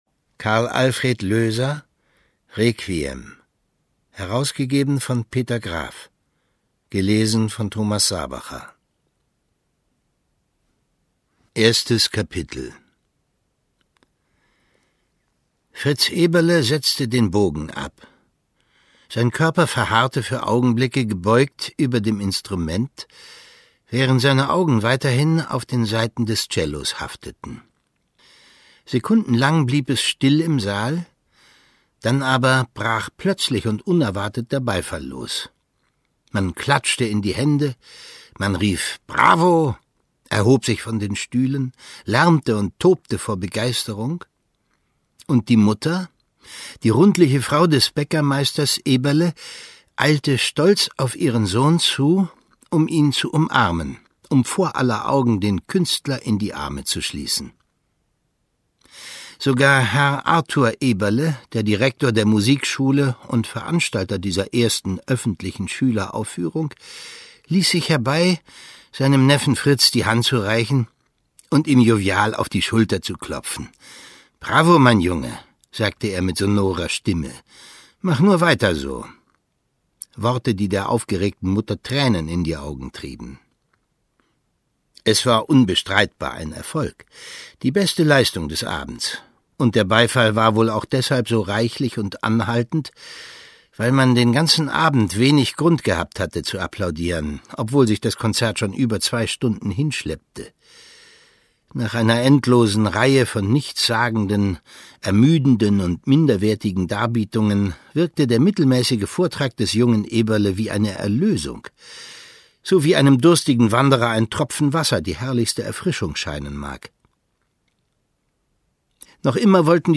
Ungekürzte Lesung mit Thomas Sarbacher (1 mp3-CD)
Thomas Sarbacher (Sprecher)